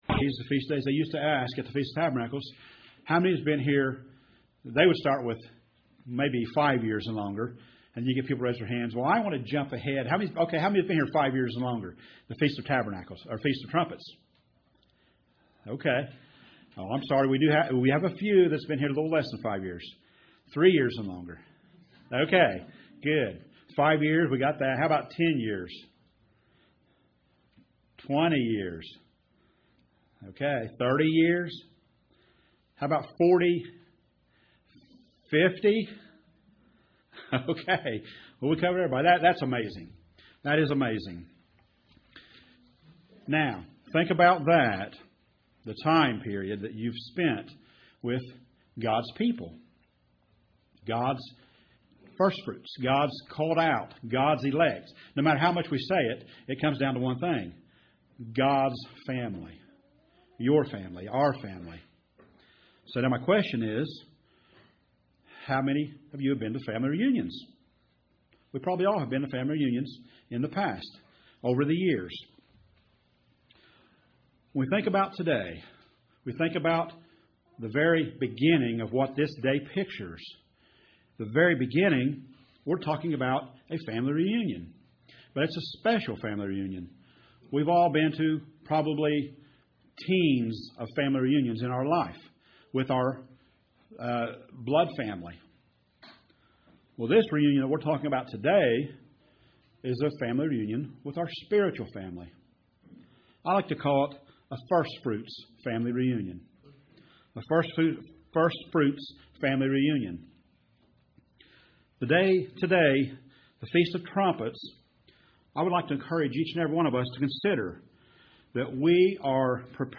Sermon on this Feast day reviews the faith of many people through out the scriptures in Hebrews 11.